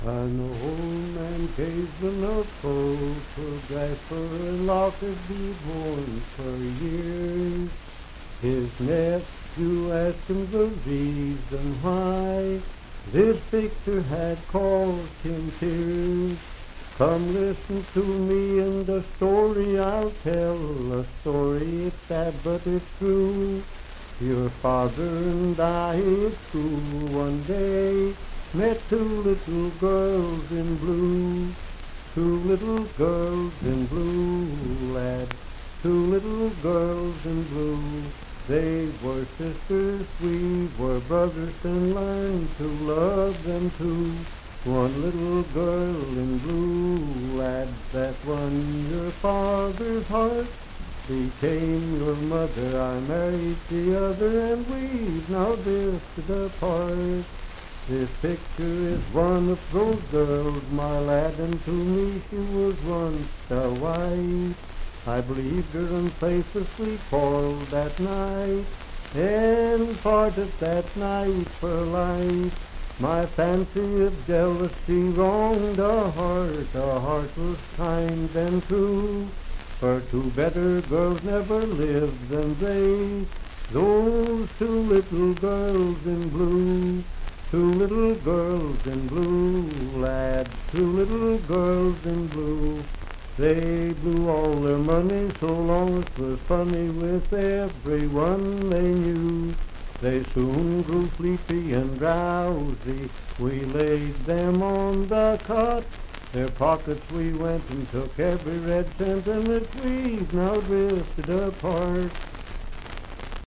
Unaccompanied vocal music
Voice (sung)
Pendleton County (W. Va.), Franklin (Pendleton County, W. Va.)